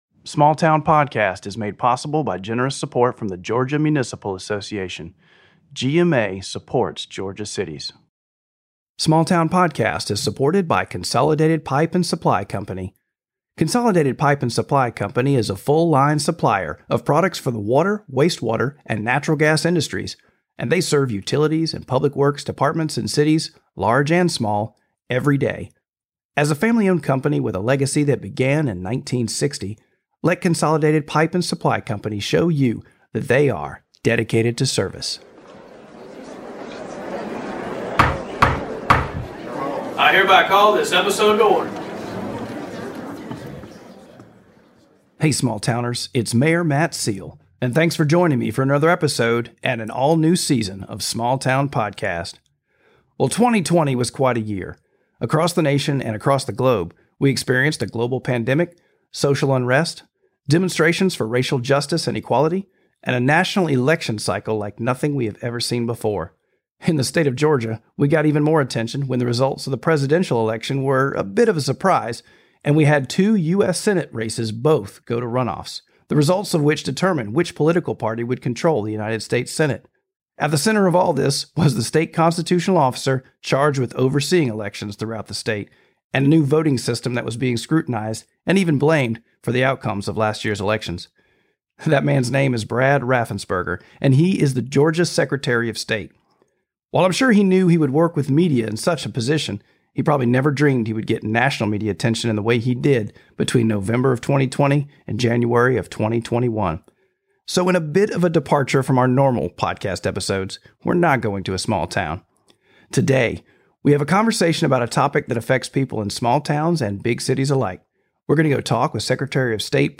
Ocilla Mayor and Small Town Podcast host Matt Seale travels to the big city of Atlanta, GA for a conversation with Georgia Secretary of State Brad Raffensperger.